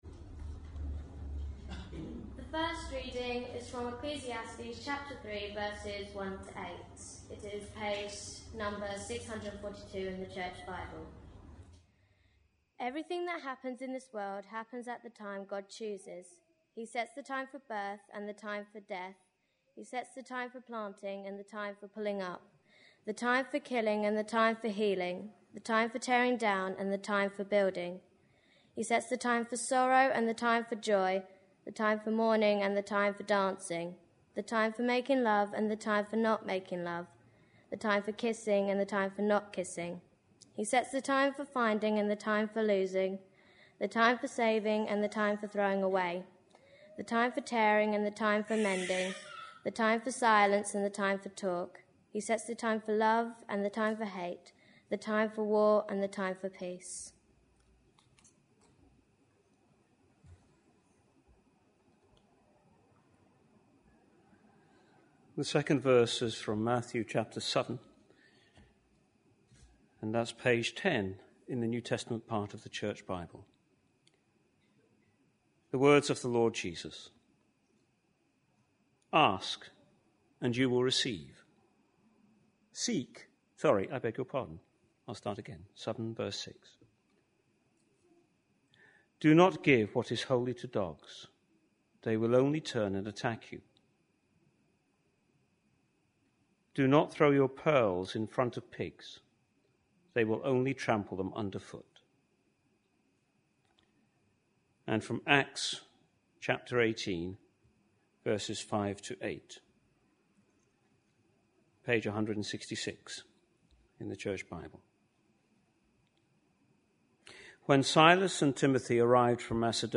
A sermon preached on 24th June, 2012, as part of our Red Letter Words series.